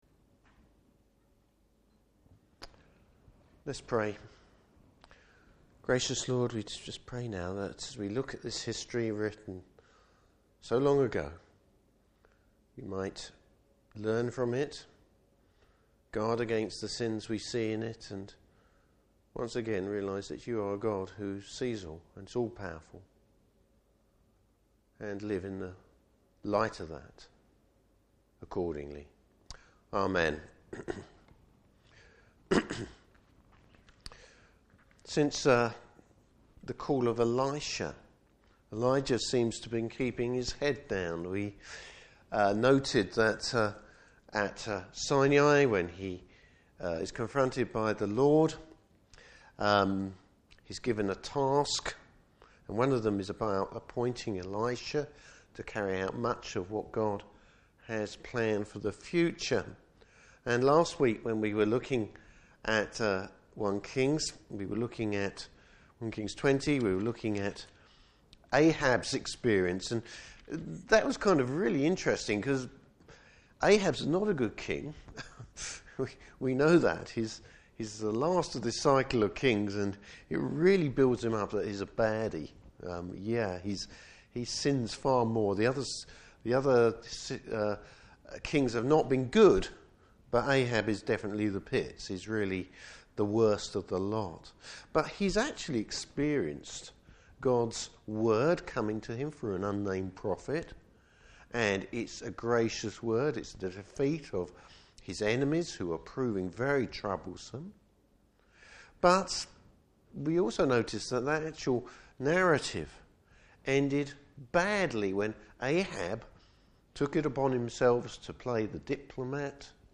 Service Type: Evening Service Bible Text: 1 Kings 21.